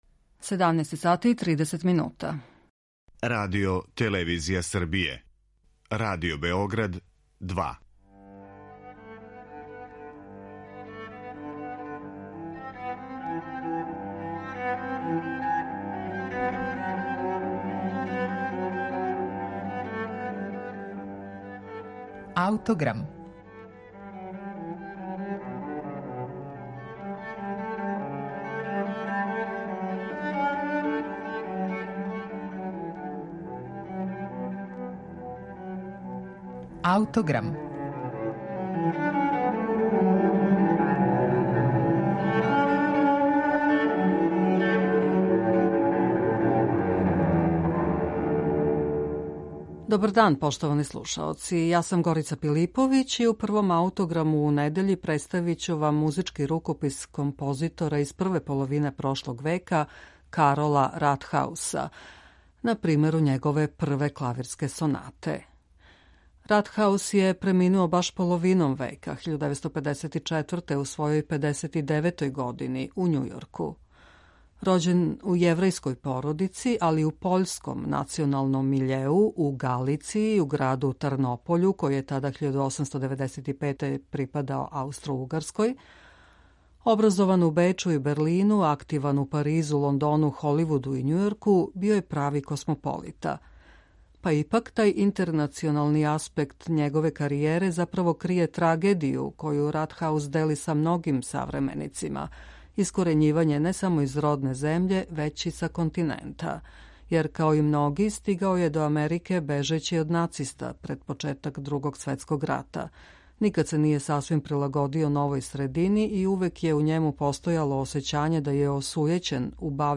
представља музички рукопис композитора из прве половине прошлог века Карола Ратхауса на примеру његове прве клавирске сонате.